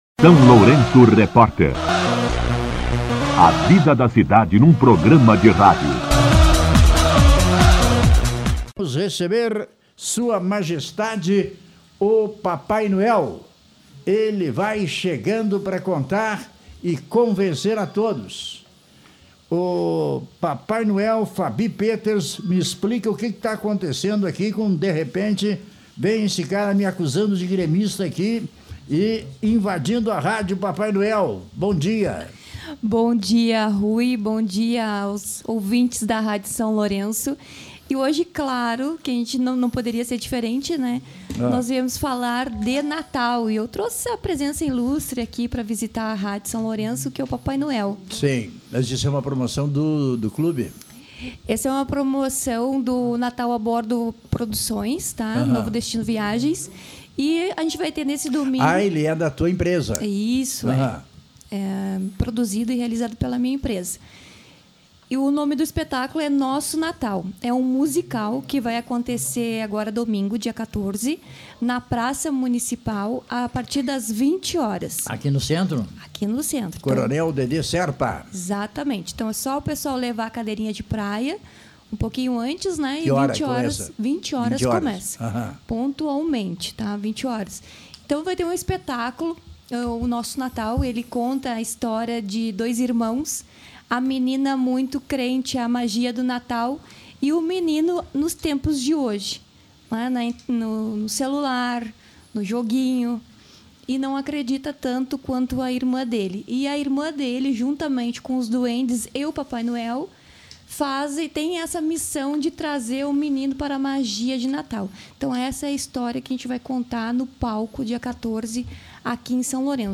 Entrevista-Papai-Noel-12.mp3